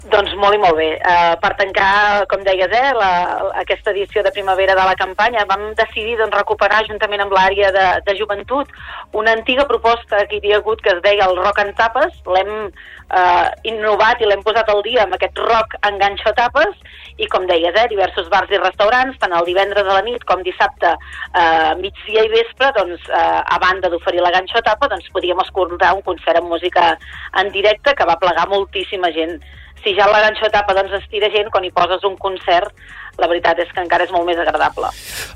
Supermatí - entrevistes
Per parlar-nos d’aquestes dues propostes ens ha visitat la regidora de turisme de Sant Feliu de Guíxols, Núria Cucharero.